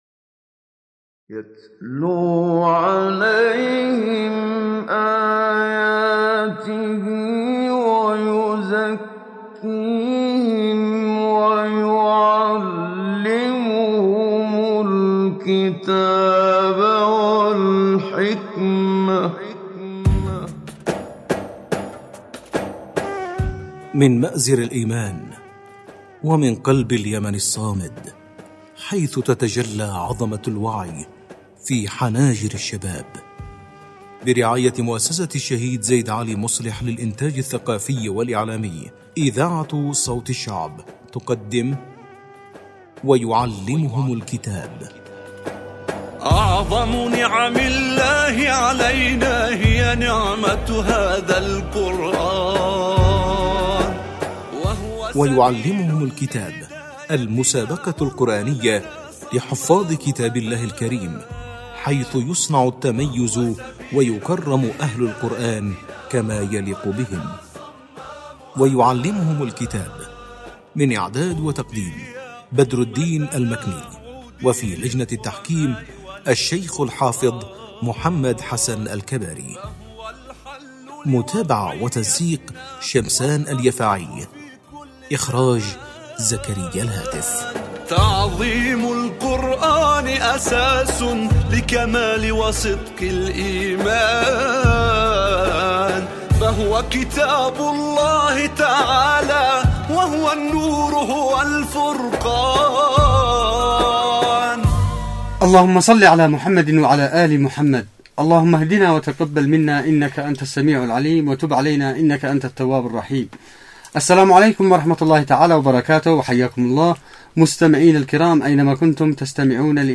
مسابقة_القرآن_الكريم_ويعلمهم_الكتاب_12.mp3